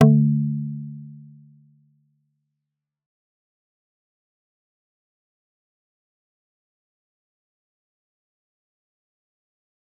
G_Kalimba-D3-f.wav